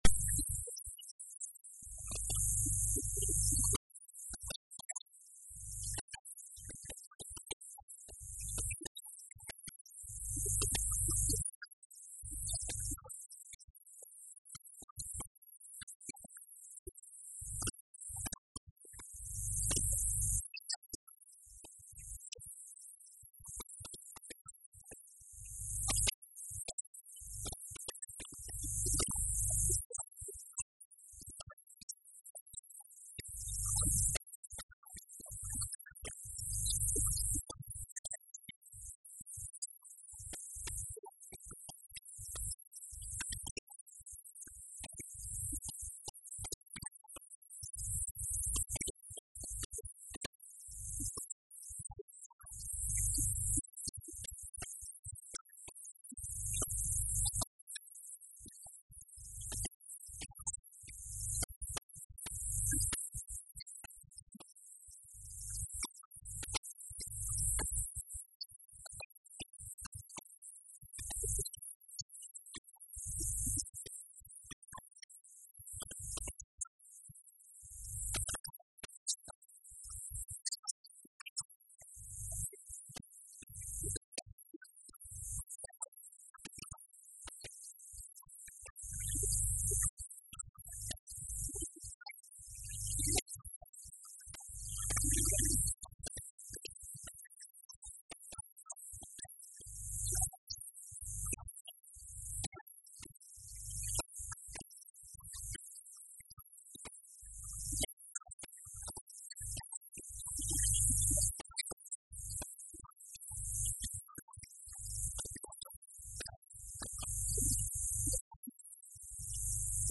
“Não temos receio do debate, achamos que a Assembleia Legislativa deve ter a oportunidade para debater este assunto”, afirmou Vasco Cordeiro, numa intervenção no parlamento regional, durante a discussão de um projeto de resolução apresentado pelo CDS/PP sobre esta matéria.